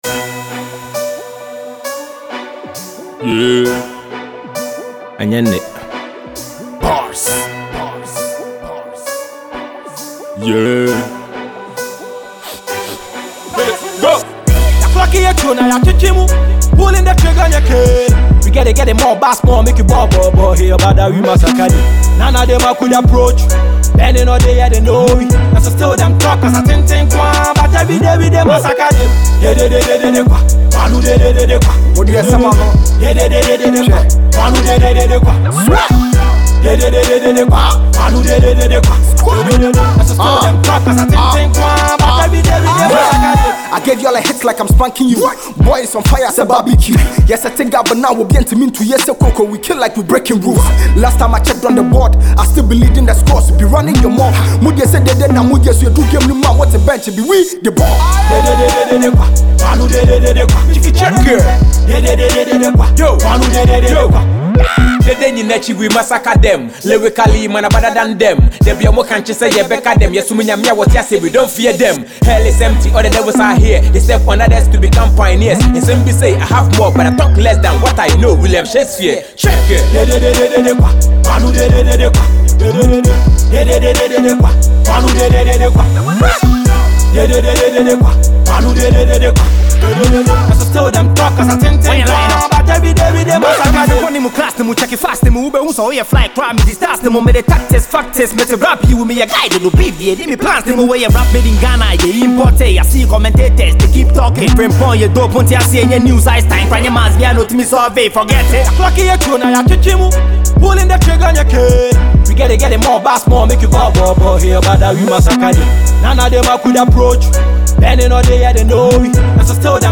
rapper
hip-hop song